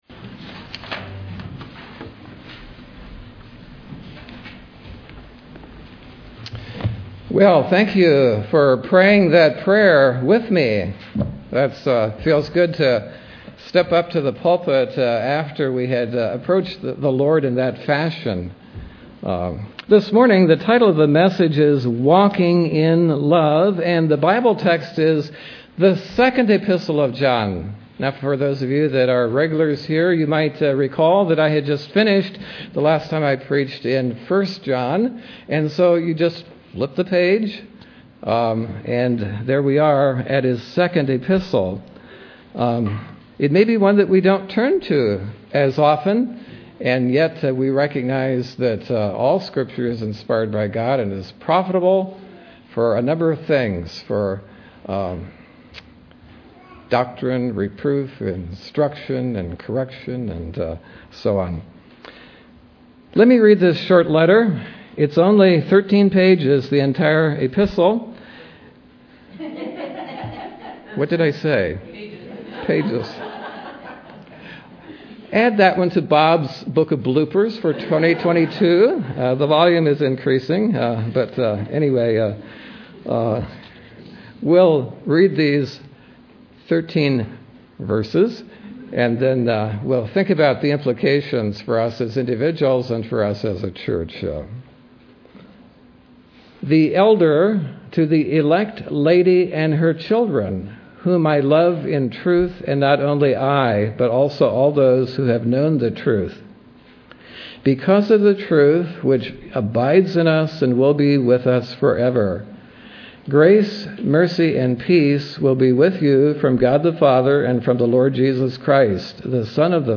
Watch Service or Listen to Sermon Copyright © 2024.